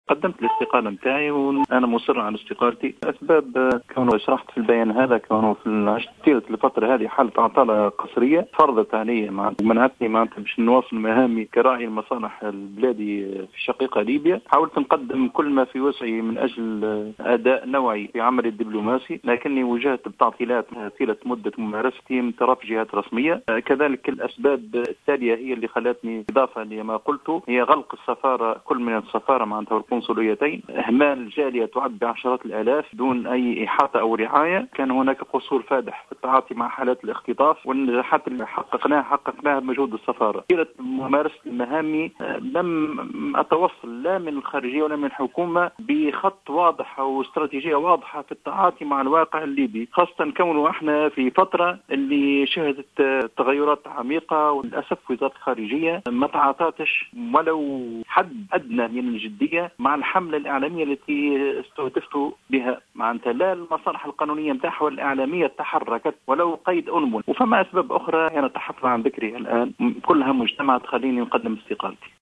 أكد سفير تونس بليبيا رضا البوكادي اليوم الخميس في تصريح ل"جوهرة أف أم" أنه قدّم استقالته لرئيس الجمهورية الباجي قائد السبسي.